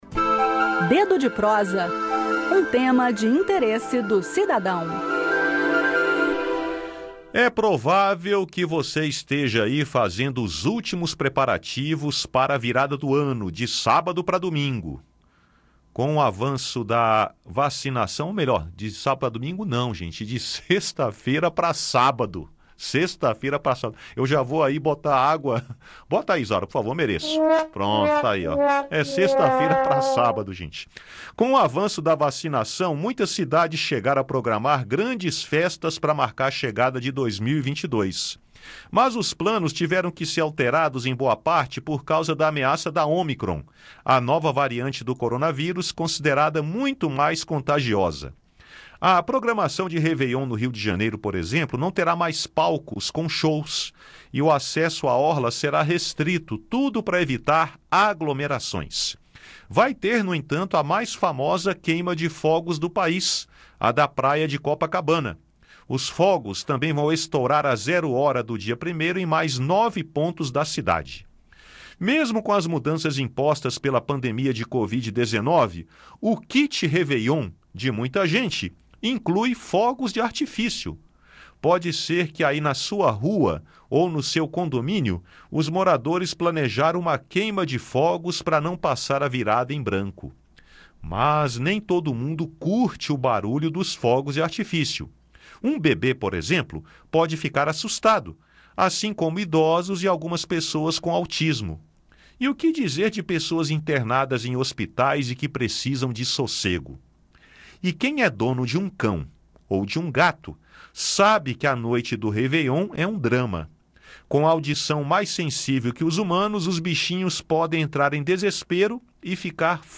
No bate-papo de hoje